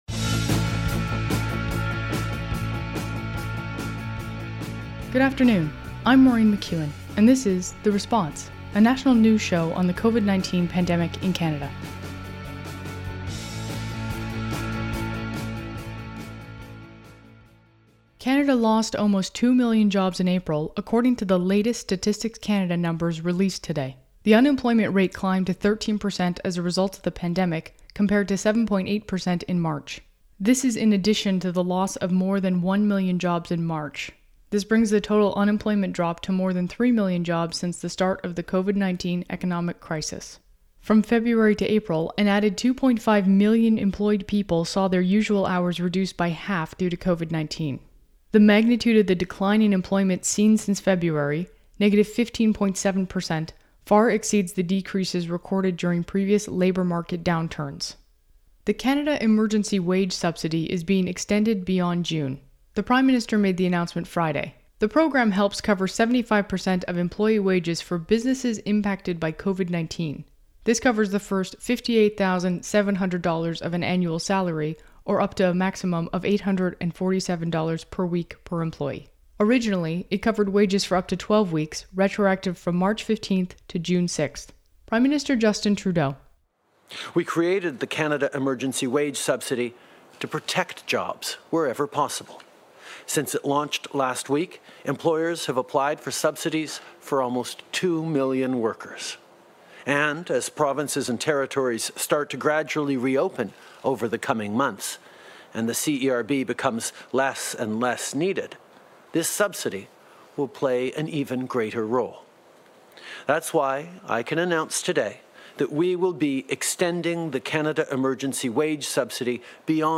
National News Show on COVID-19
Recording Location: Ottawa
Type: News Reports